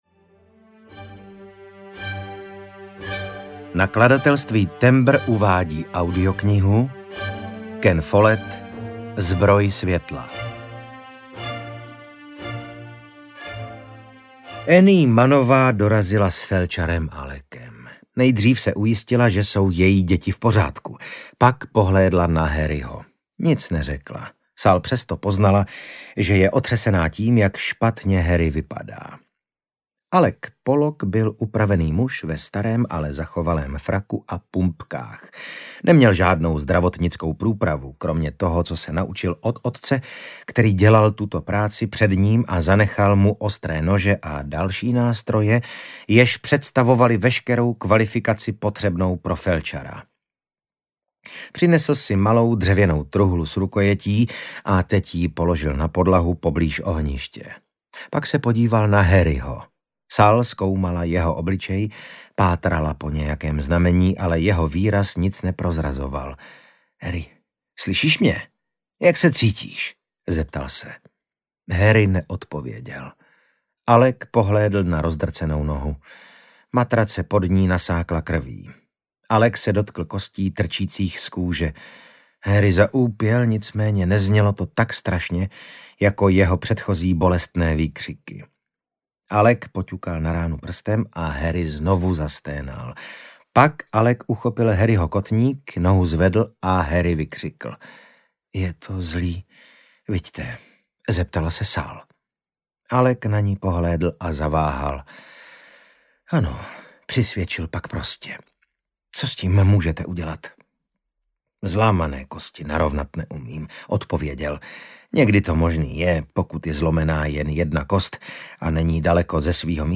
Čte Vasil Fridrich
Natočeno ve studiu All Senses Production s. r. o.
• InterpretVasil Fridrich